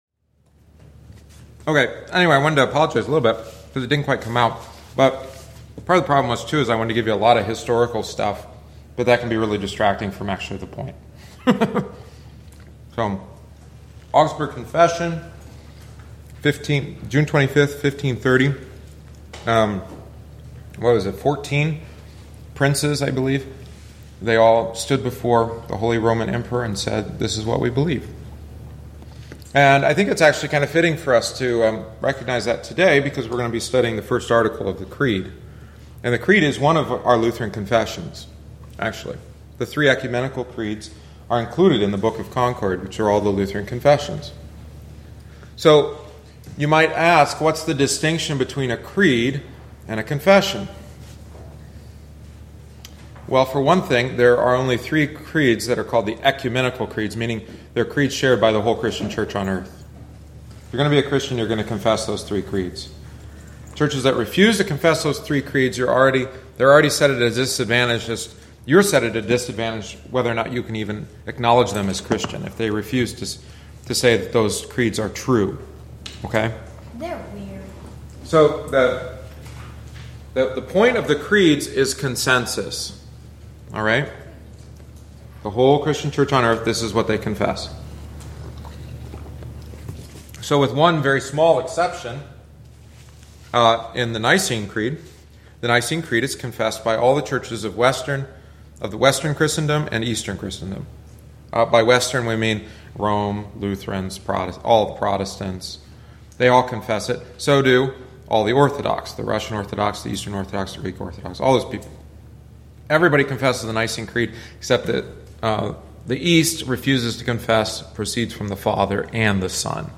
Join us for Adult Catechumenate classes following each Wednesday Divine Service. This is offered for those that would like a refresher course on their catechetical instruction and especially for those desiring to join us and confess the Lutheran faith.